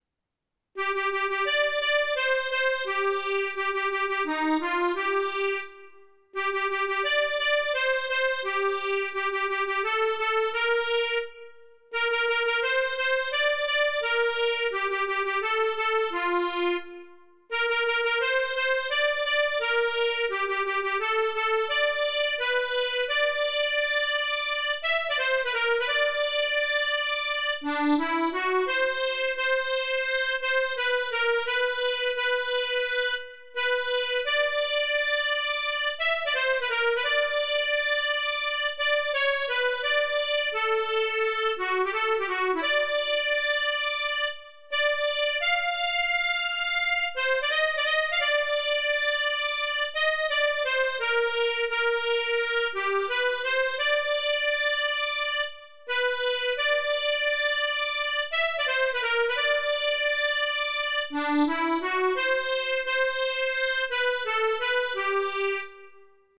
Soirs-de-Blidah-Accordéon.mp3